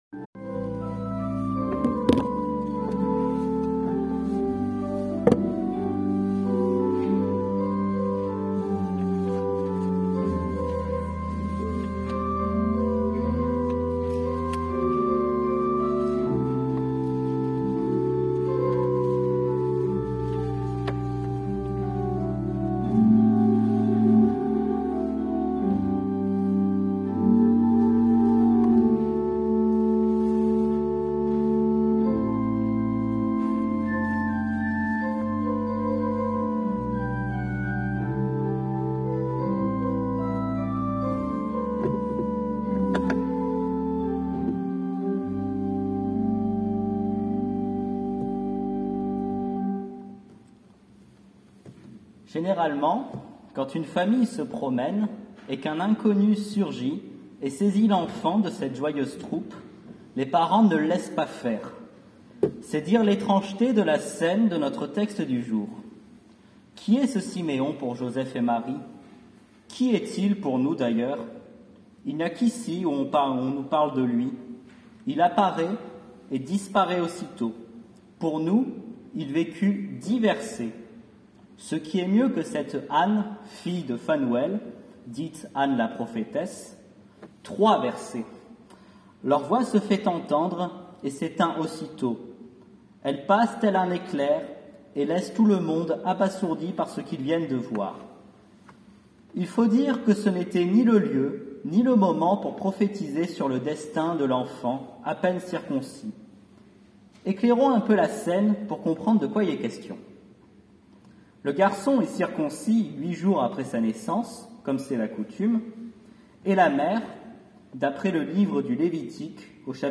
PRÉDICATION DU 2 FÉVRIER 2025.pdf (939.67 Ko) Prédication du 2 février 2025.mp3 (41.41 Mo)